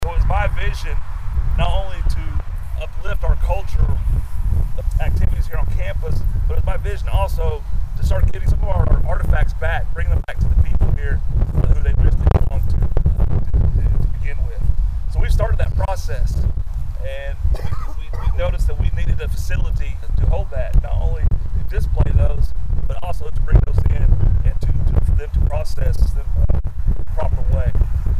The Delaware Tribe of Indians held a groundbreaking event on Friday morning at their tribal complex in Bartlesville.
Delaware Chief Brad KillsCrow talked about the vision behind the cultural center.